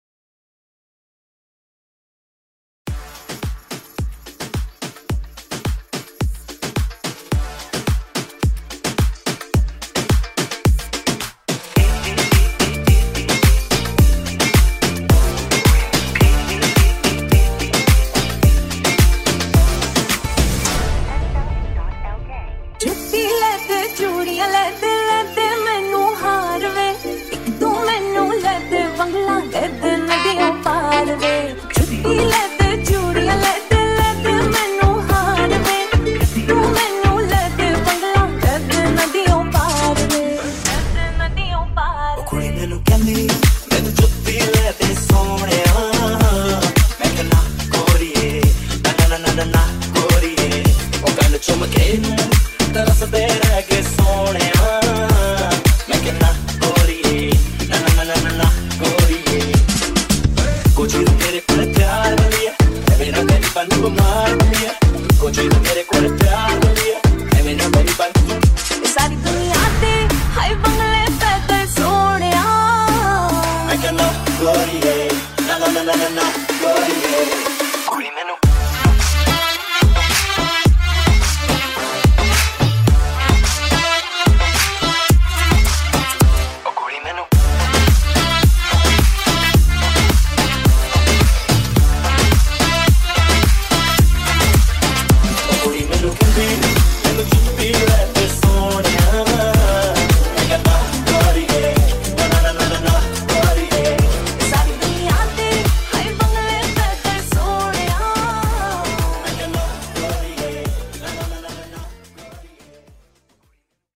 High quality Sri Lankan remix MP3 (2).